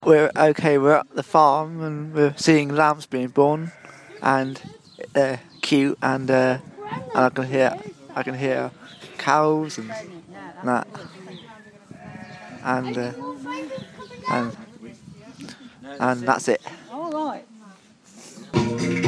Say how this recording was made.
lambing outside broadcast